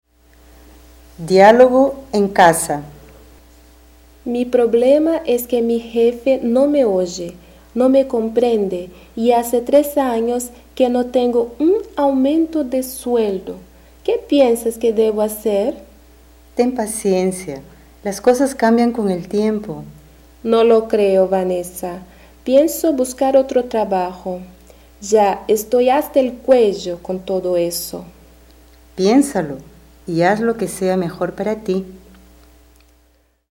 Description: Áudio do livro didático Língua Espanhola I, de 2008. Diálogo com expressões populares.